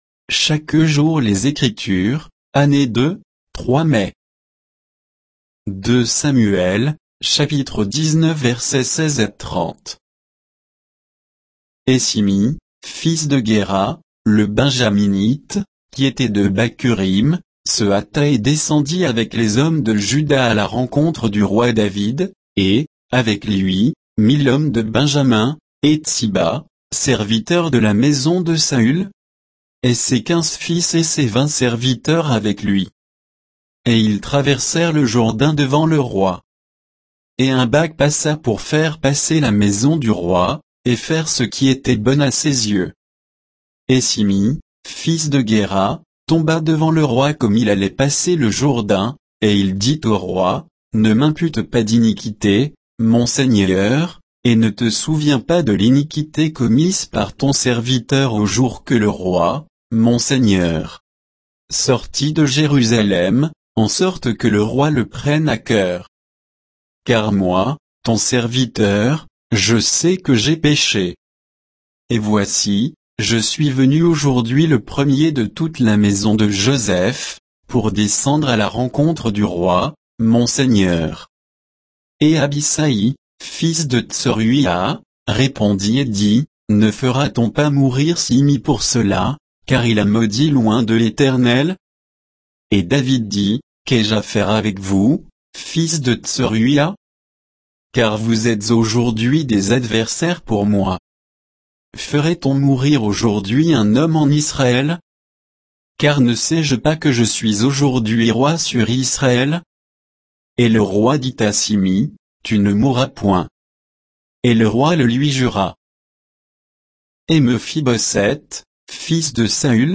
Méditation quoditienne de Chaque jour les Écritures sur 2 Samuel 19